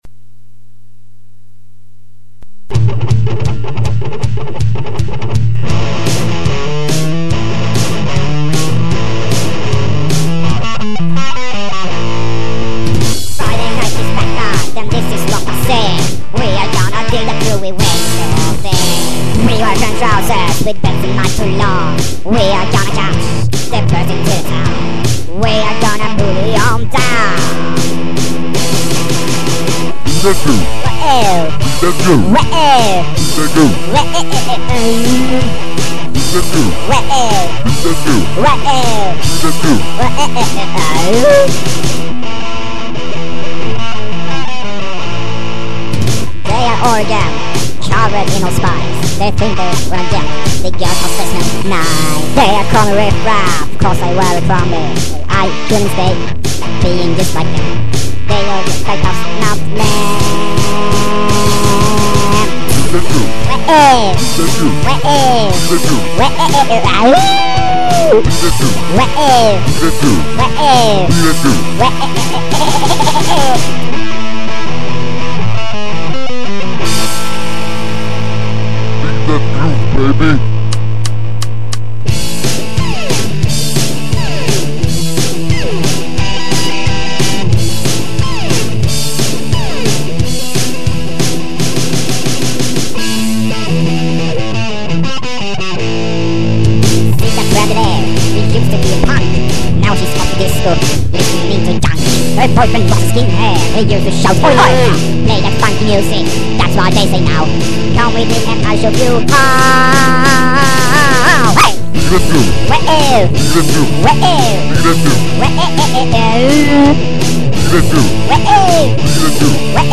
We are two guys (15 years old) from Sweden.